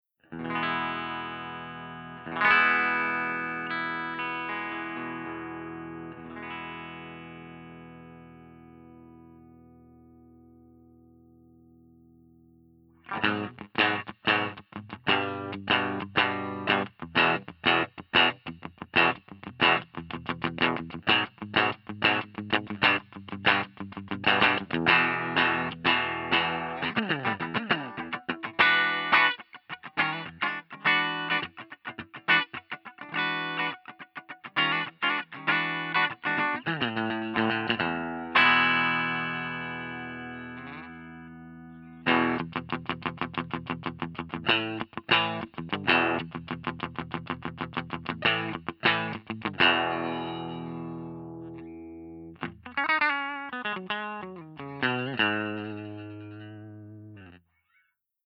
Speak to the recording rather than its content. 042_FENDER75_BRIGHT_SC.mp3